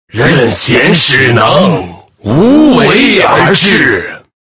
guzheng1.mp3